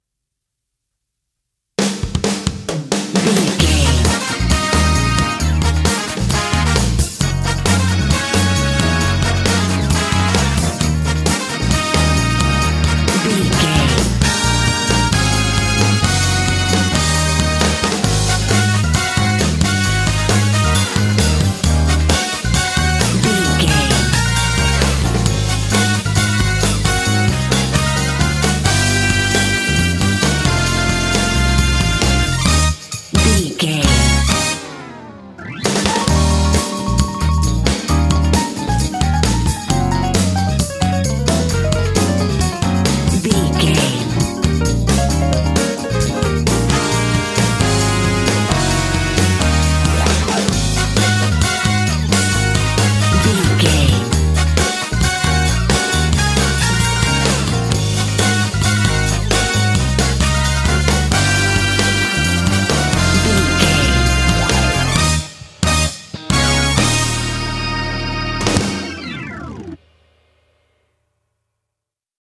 Uplifting
Aeolian/Minor
E♭
smooth
groovy
brass
bass guitar
electric organ
electric guitar
drums
piano
soul